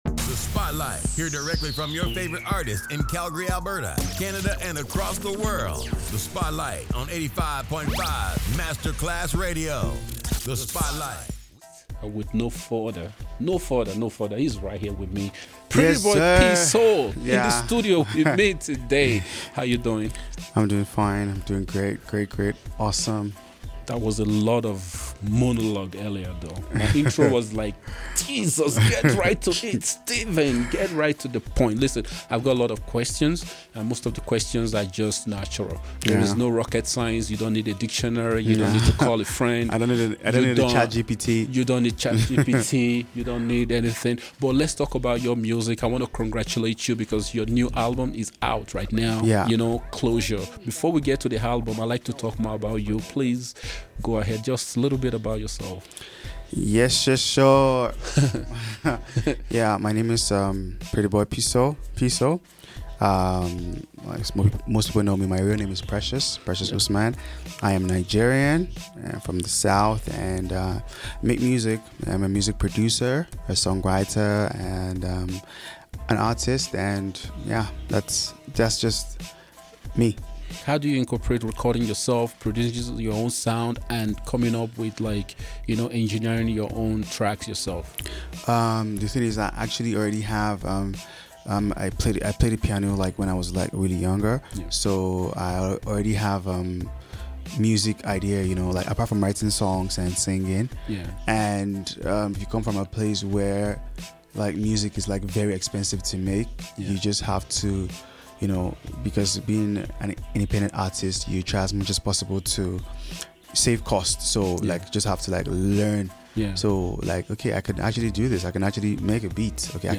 Play Rate Listened List Bookmark Get this podcast via API From The Podcast All posted contents were live broadcast and have been made available for your listening pleasure.